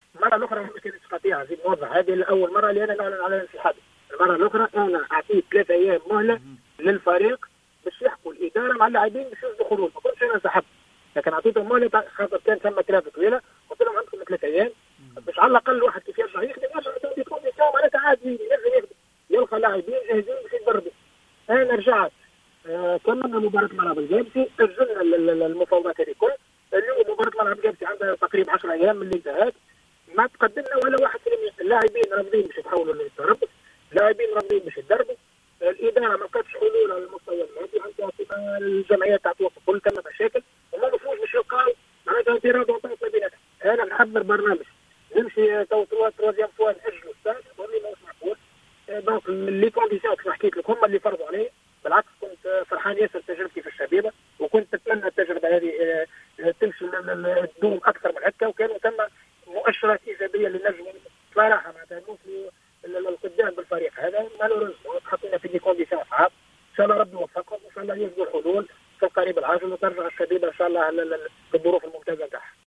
أكد المدرب جلال القادري في تصريح لجوهرة أف أم إنسحابه رسميا من تدريب شبيبة القيروان مضيفا أن الأسباب تعود بالأساس لعدم توفر الظروف الملائمة للعمل بالإضافة إلى غياب مسؤولي النادي.